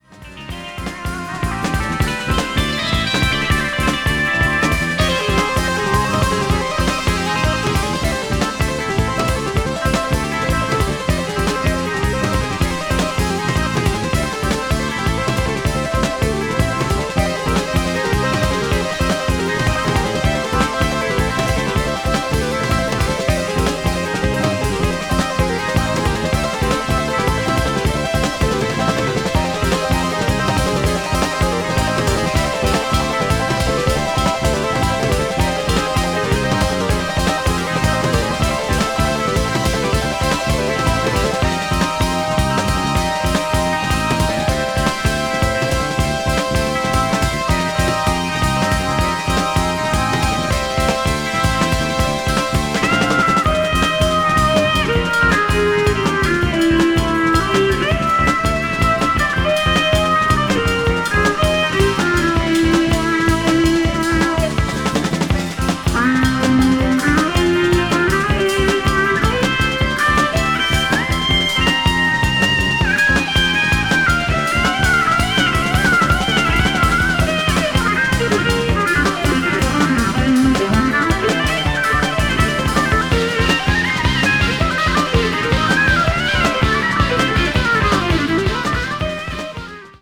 crossover   fusion   progressive rock